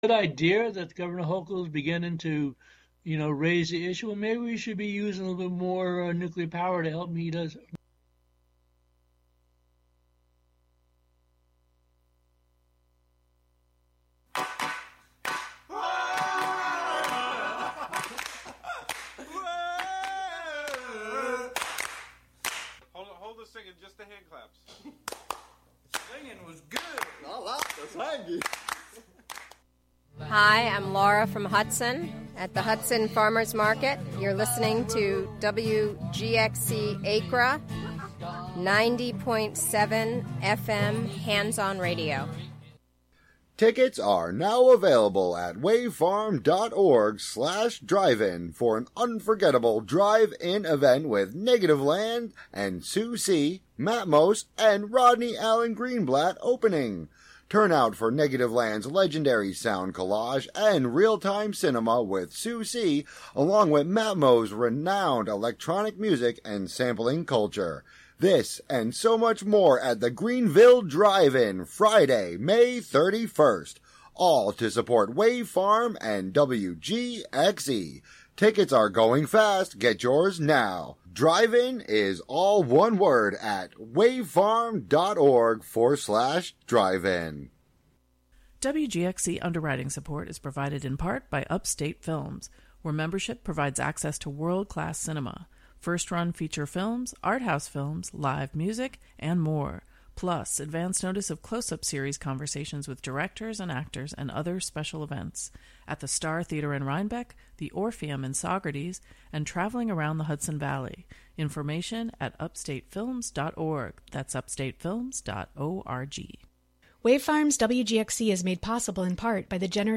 Here, listeners engage with the host, other expert guest educators, activists, organizers, and community members as they disseminate their knowledge (ie. varying terminologies, historical realities, and curren